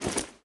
UI_OpenBag.ogg